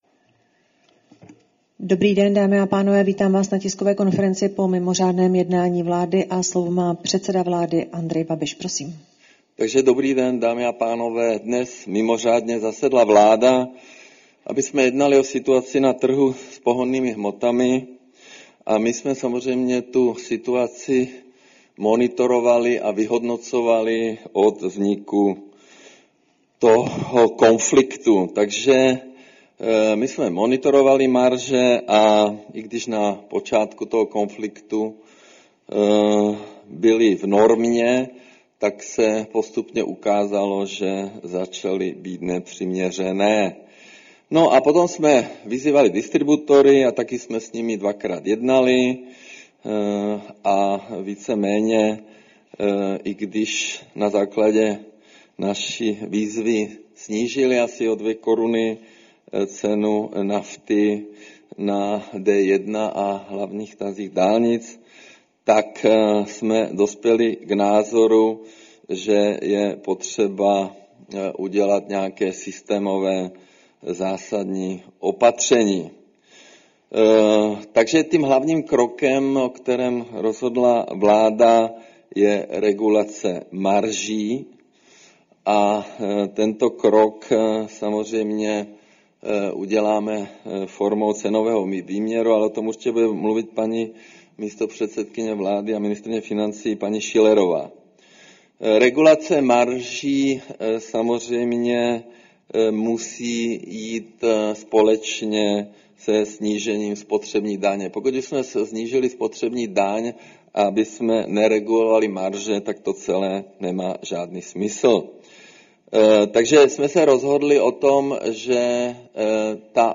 Tisková konference po jednání vlády, 2. dubna 2026